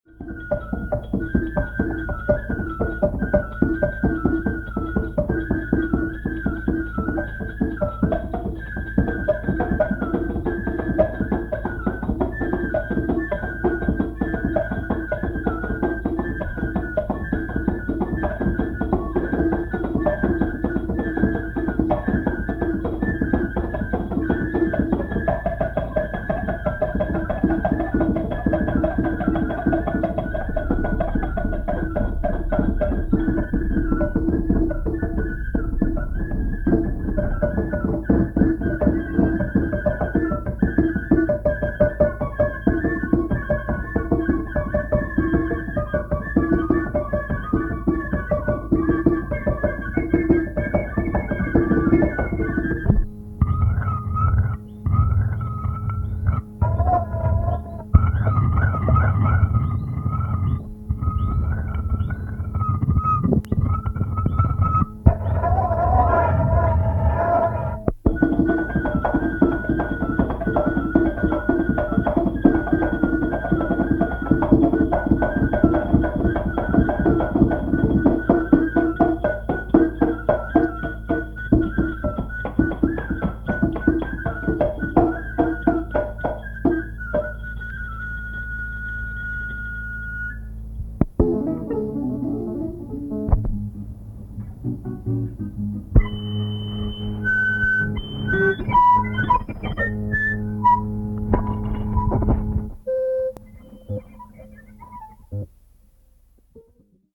prepared piano